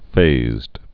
(fāzd)